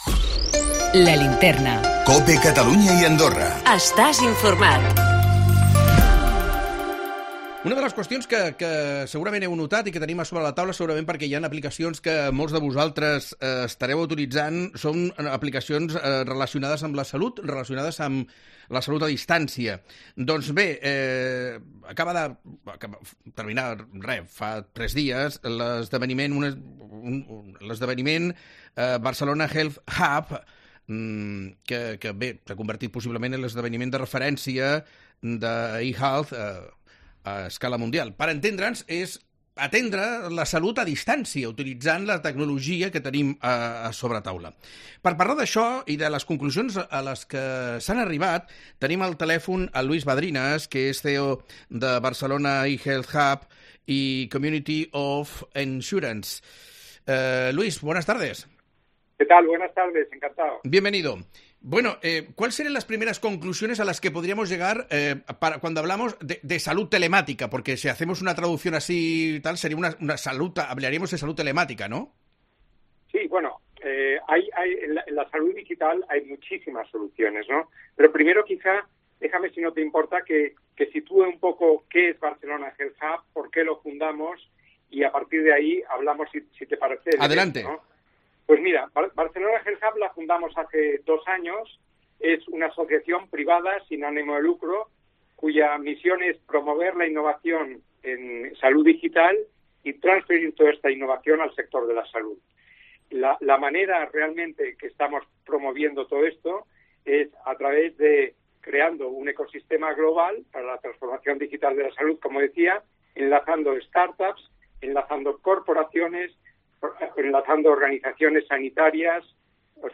Per tractar el tema avui, a La Linterna Catalunya, hem convidat al programa